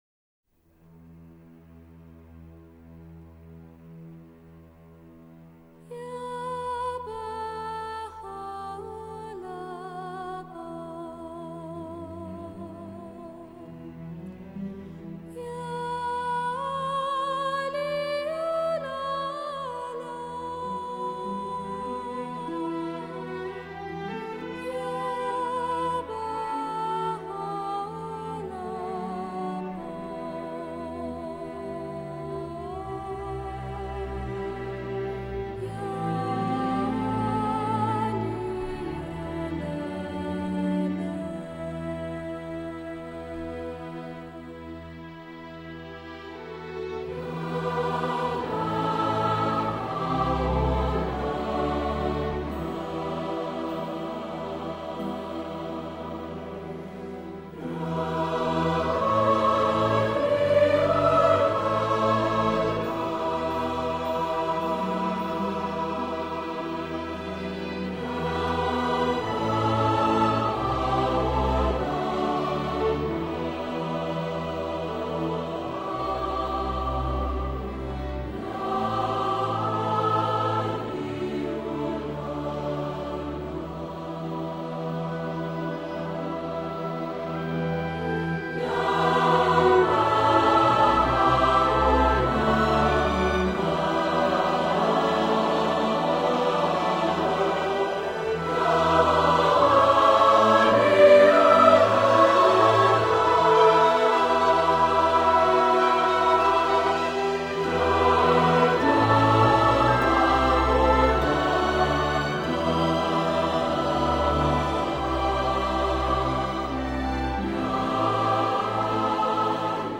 کنگره جهانی دوم بهائیان در نیویورک سال 1992